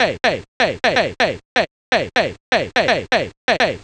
cch_vocal_loop_ayy_125.wav